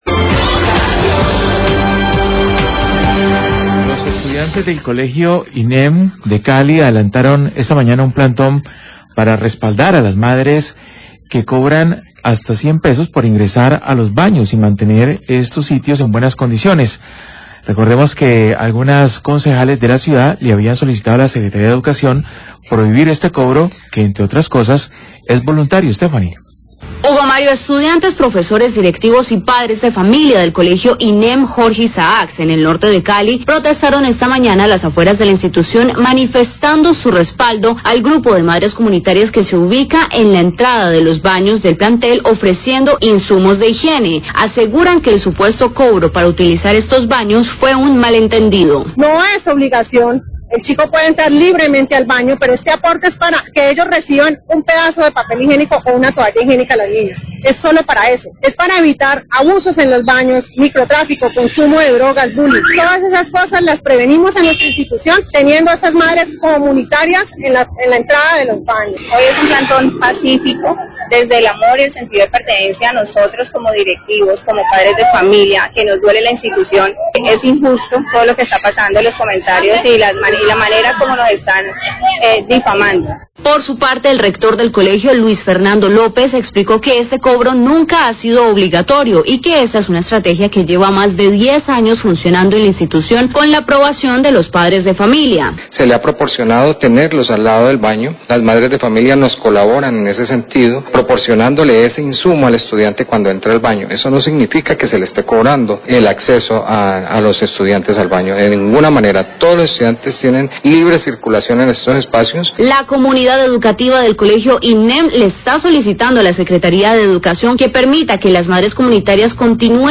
Radio
La comunidad educativa del colegio INEM hace un plantón con el fin de protestar por la decisión de laa Secretaría de Educación de prohibir el cobro a la entrada de los baños. Afirman que hay un malenteneido y que no es cobro sino aporte voluntario. Hablan integrantes de la comunidad.